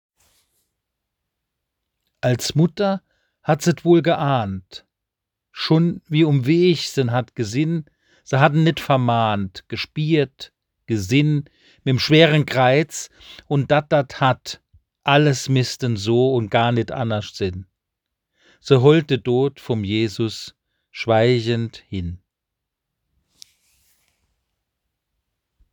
Es enth�lt eine Tonversion der obenstehenden moselfr�nkischen Kreuzwegbetrachtung Sie k�nnen es unter diesem Link abrufen.